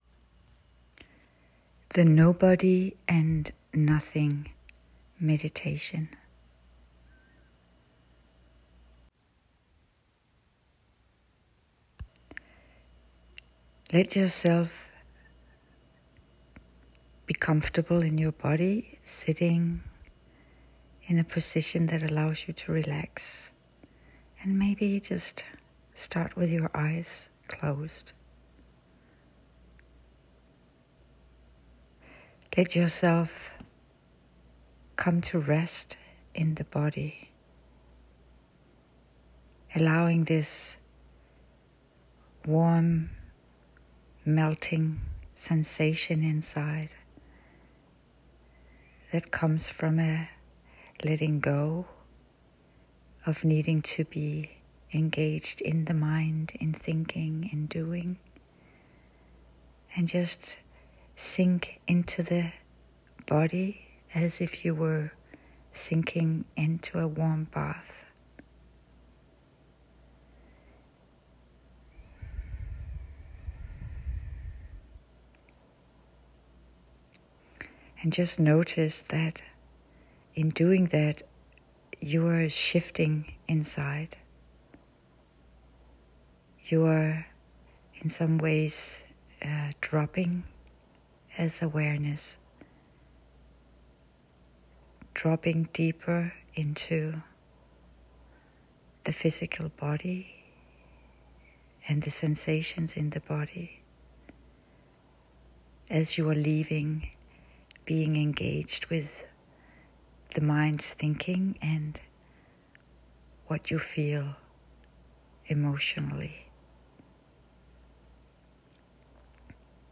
A guided meditation inviting you to drop into a deep rested beingness. To move beyond a self of worry, stress and anxeity – to connect with a much deeper level of you.